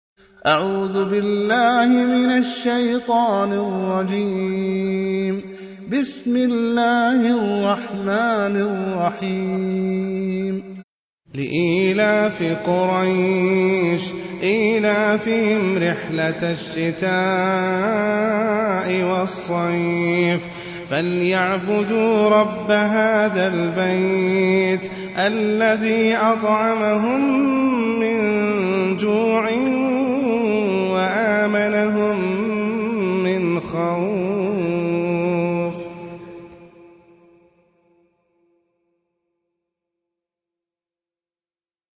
Surah Quraish سورة قريش Audio Quran Tarteel Recitation
Surah Sequence تتابع السورة Download Surah حمّل السورة Reciting Murattalah Audio for 106.